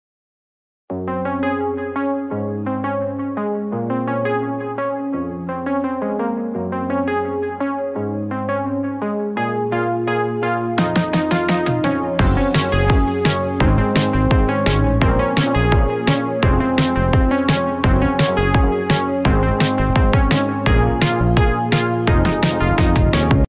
Blues Ringtones